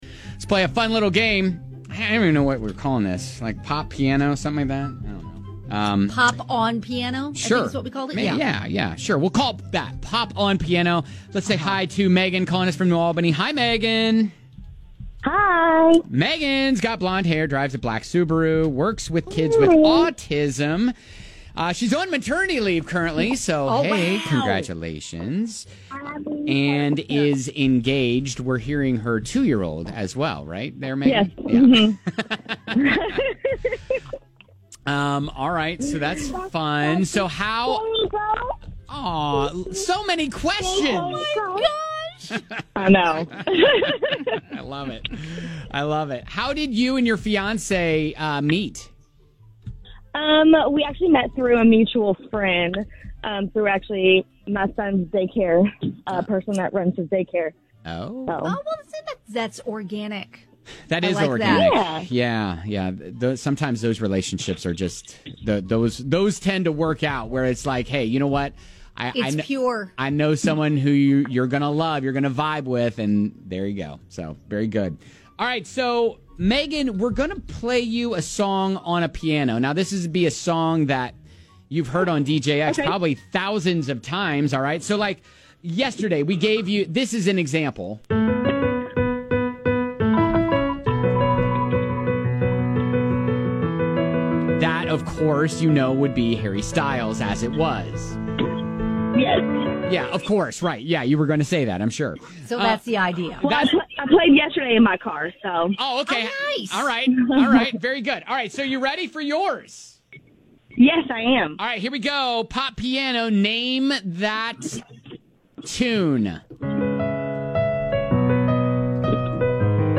There are playlists on Spotify and YouTube where they do popular pop songs on the piano. It is harder to identify than you would think. We're putting you to the test!